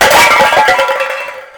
trap_tripwire_cans_2.ogg